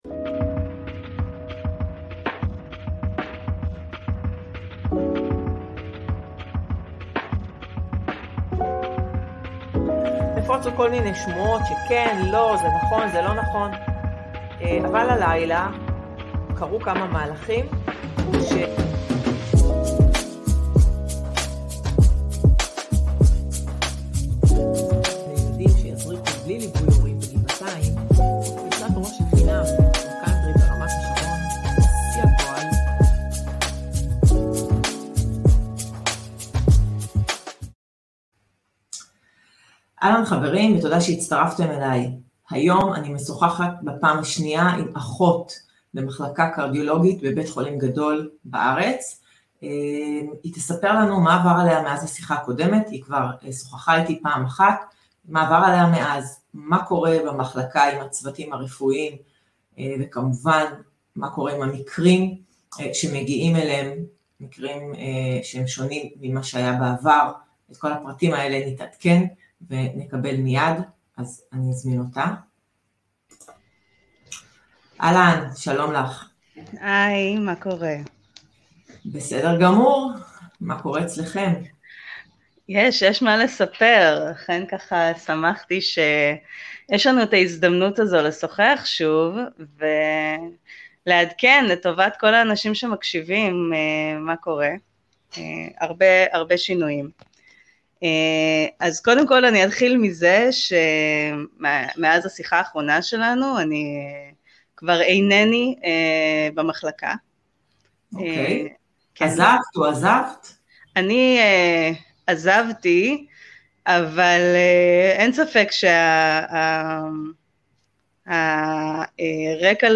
אחות מחלקה קרדיולוגית מדברת על המקרים הקשים, מצוקת הצוות ואוירת ההסתרה